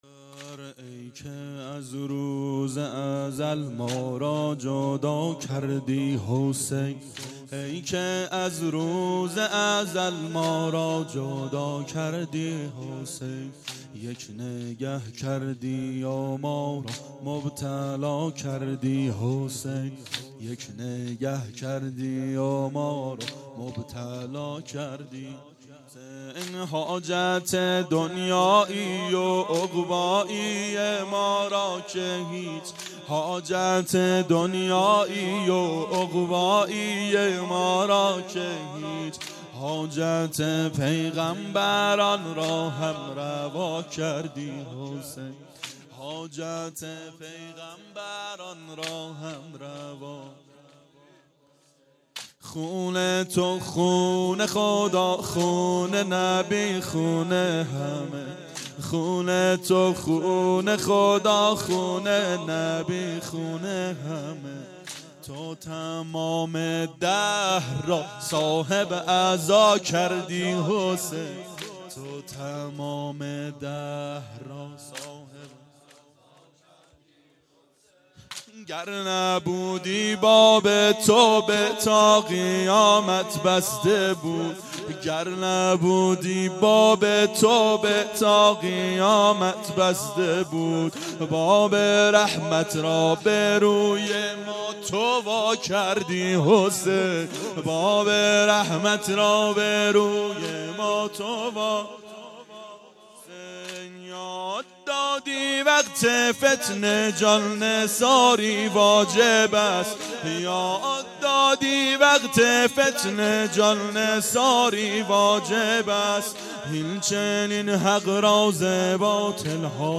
ای که از روز ازل مارا جدا کردی حسین / سنگین
• شب شهادت حضرت زهرا سلام الله علیها 93 عاشقان اباالفضل علیه السلام منارجنبان